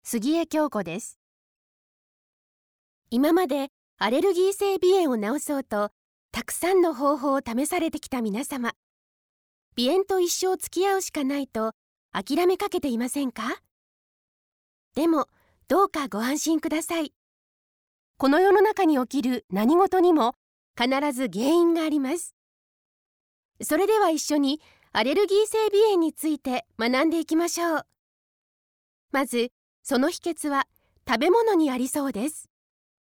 ※「Dowonload」ボタンを右クリック保存で、 ボイスサンプルをダウンロードできます。